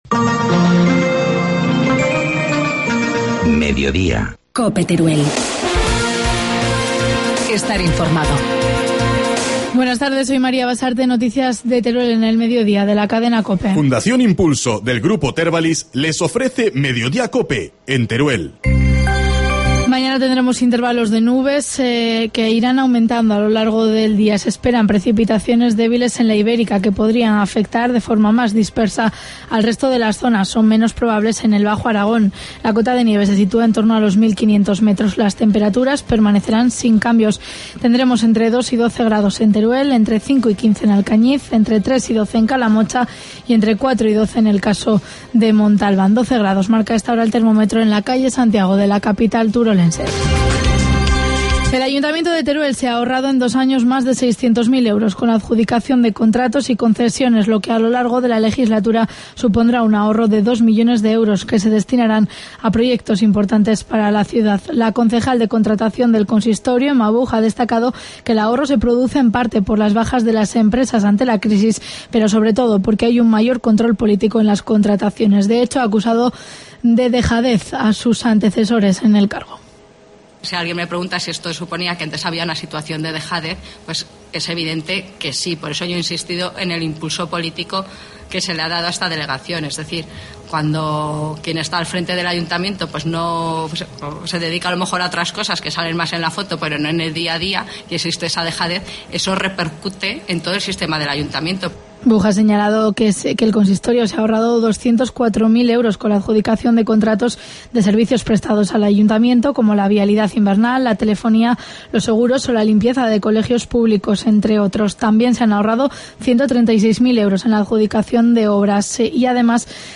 Informativo mediodía, miércoles 20 de febrero